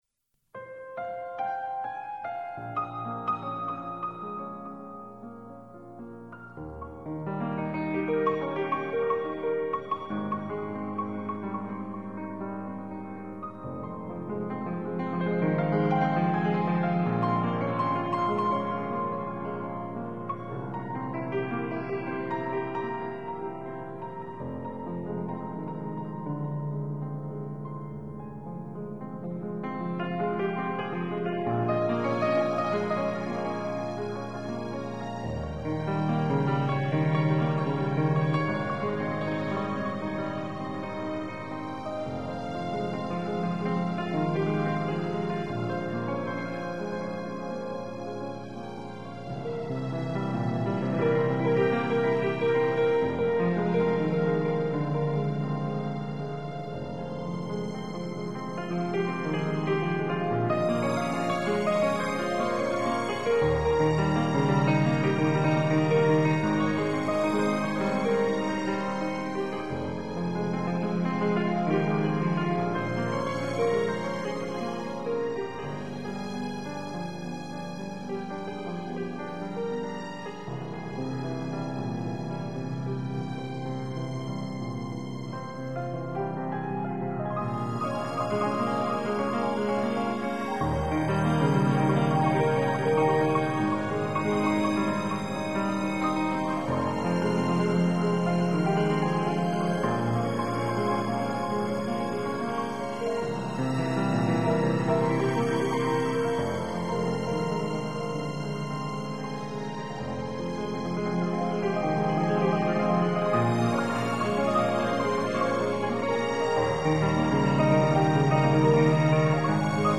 慢慢聽吧，相信大夥會跟我一樣，愛上這樣的琴，這樣用情感去敲擊而出的抒情鋼琴。